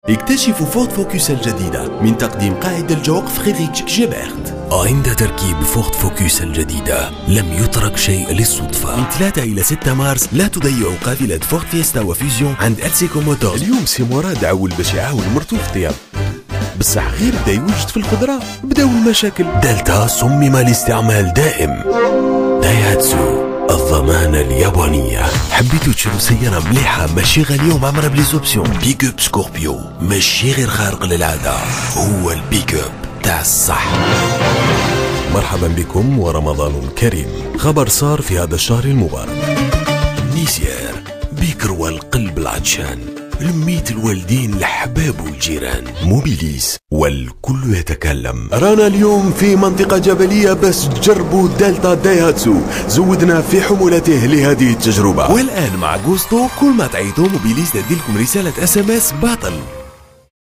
Comédien voix off en langue arabe, réalisateur radio, chanteur, imitateur depuis plus de 20 ans, je met chaque jour ma voix et mon expérience à votre service pour tout enregistrement de voix : pub radio ou TV, commentaire de film institutionnel, film d\'entreprise, habillage d\'antenne, audiotel, billboard, composition, cartoon, comédie radio, bande annonce, signature, attente téléphonique, jingle, voice over....
Sprechprobe: Sonstiges (Muttersprache):